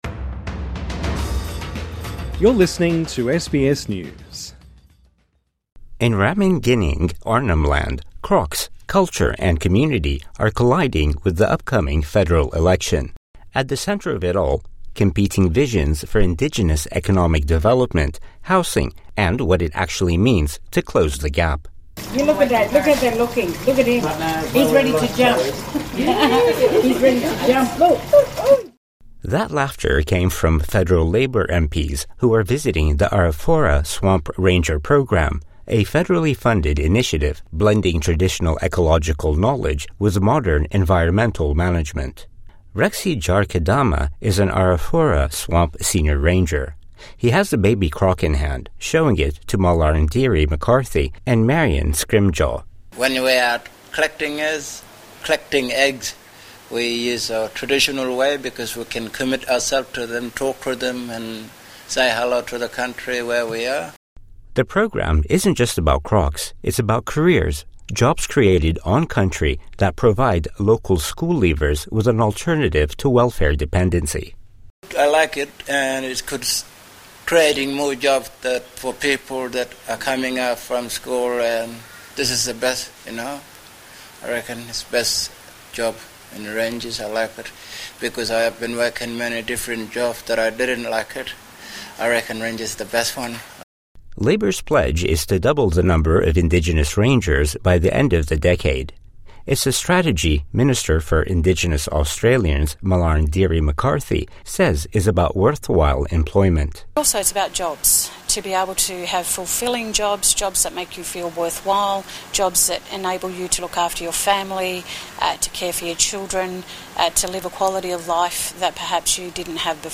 Housing, employment, and remote road infrastructure are key issues for voters in remote regions of the Northern Territory. As campaigning continues for the upcoming federal election on May 3rd, SBS travelled to the community of Ramingining, around 600 kilometres east of Darwin, where the Minister for Indigenous Australians took her pitch to voters this week.